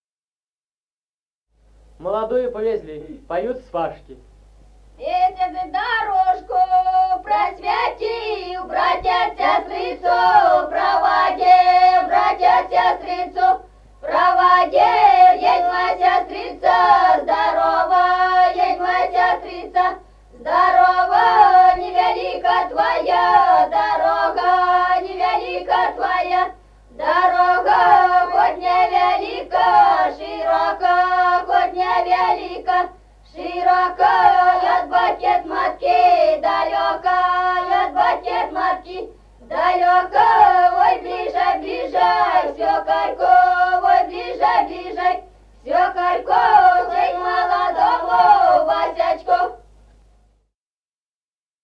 Музыкальный фольклор села Мишковка «Месяц дорожку просветил», свадебная.